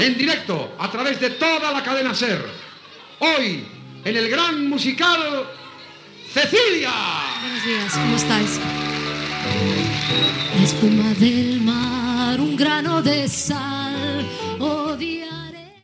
Identificació del programa i presentació de la cantant Cecilia.
Musical